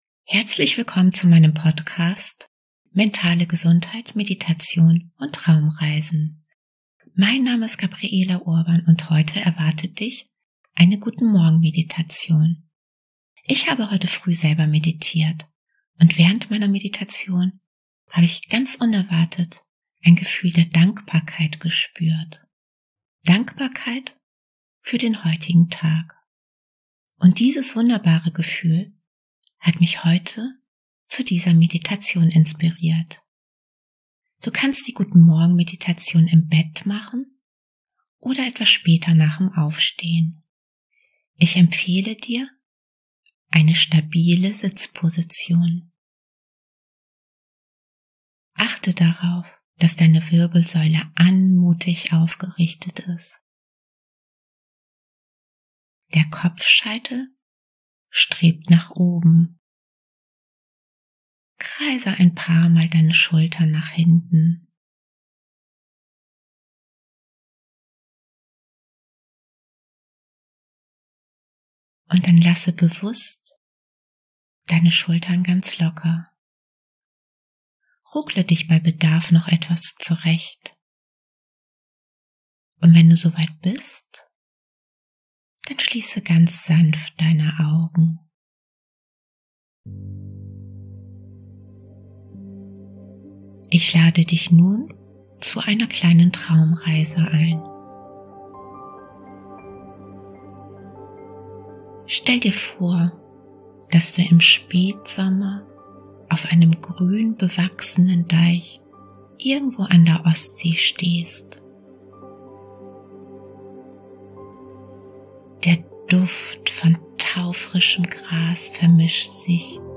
#066: Guten Morgen Meditation – mit Dankbarkeit in den Tag starten